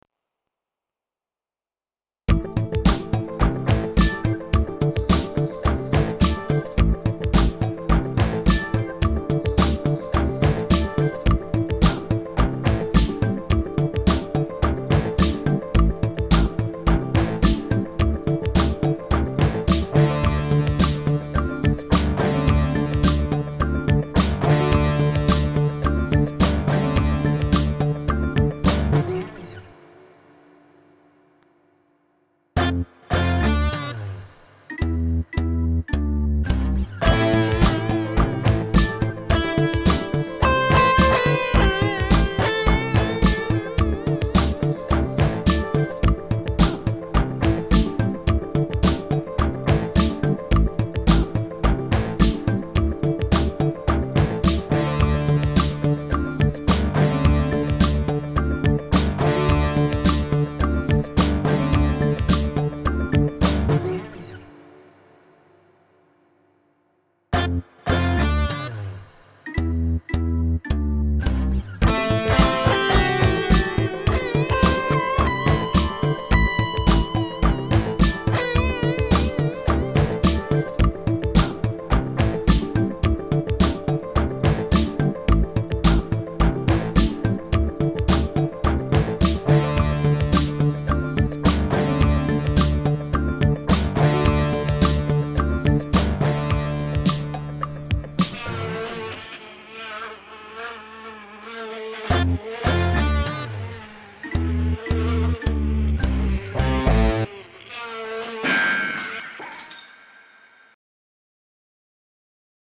• Качество: Хорошее
• Категория: Детские песни
Слушать минус